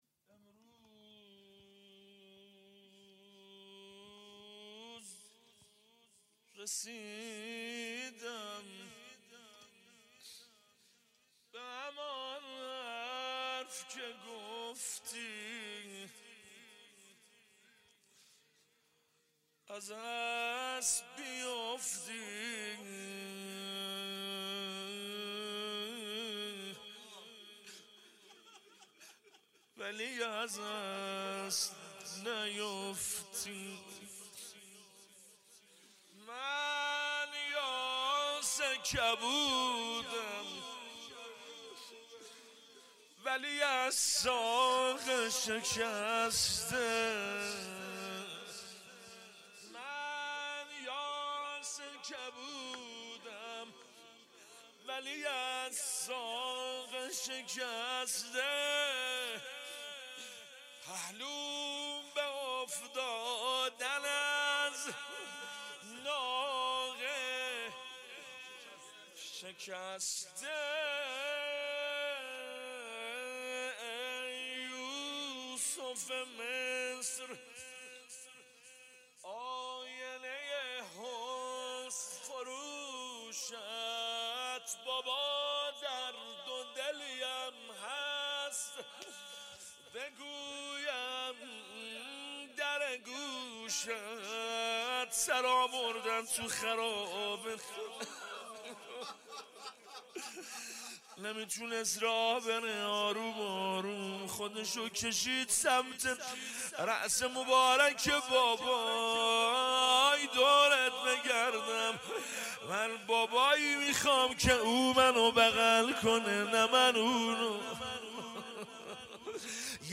روضه _ امروز رسیدم به همان حرف که گفتی